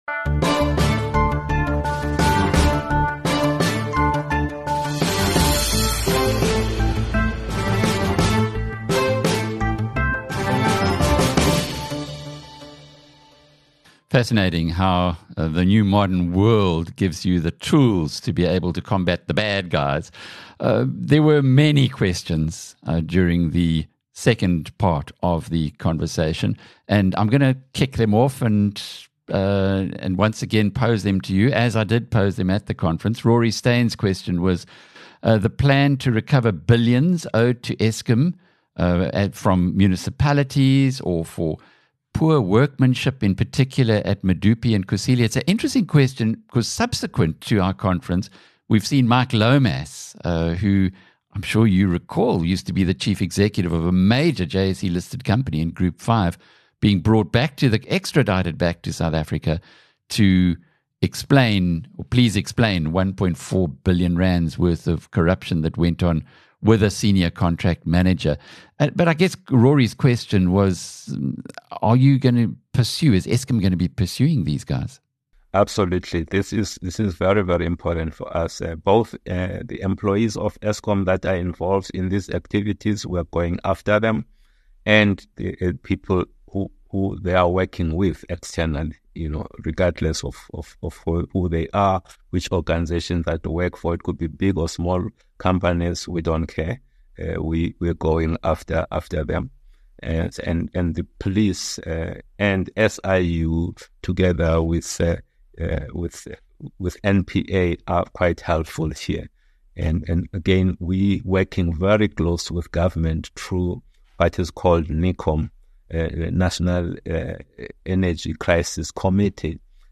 In this Q&A session with the BizNews tribe, Eskom chair Mteto Nyati addressed critical issues surrounding South Africa's energy crisis, particularly focusing on the ongoing challenges of loadshedding. He reflected on the company's past struggles, emphasizing the need for a data-driven approach to enhance efficiency and reduce operational costs.